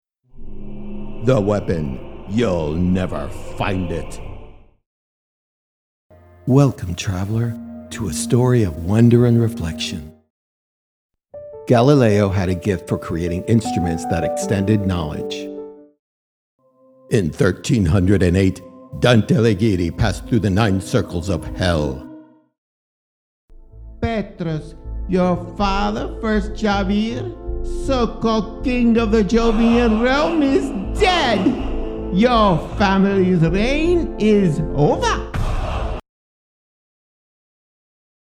English Character (.38)
A collection of vibrant character voices perfect for animation, games, and audiobooks. From youthful energy to seasoned gravitas.
All content recorded in Studio 23, Nashville, Tennessee.
English-Character-Reel.mp3